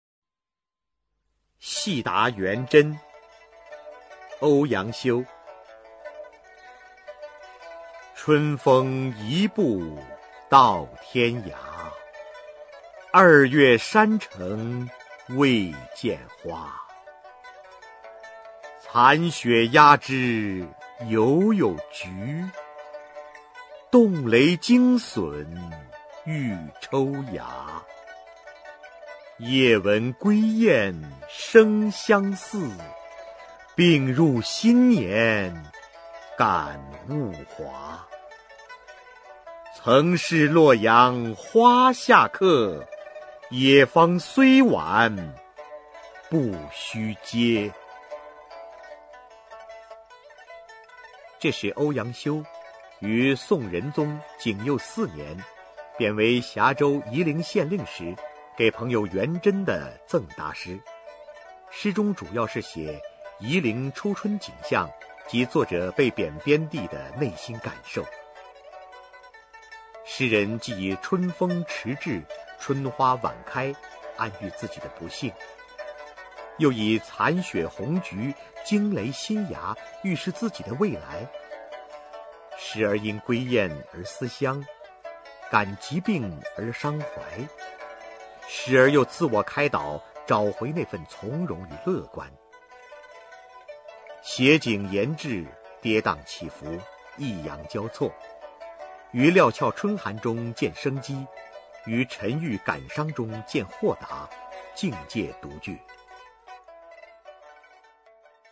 [宋代诗词朗诵]欧阳修-戏答元珍 古诗词诵读